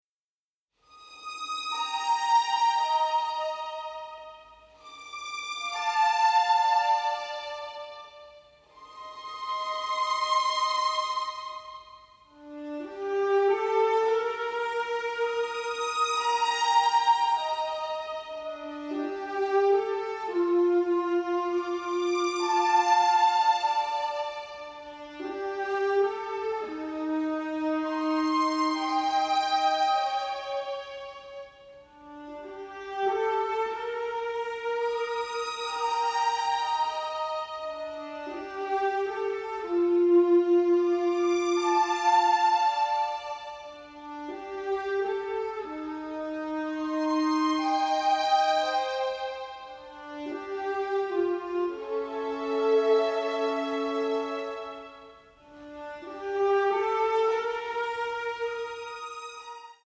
mastered from the original tapes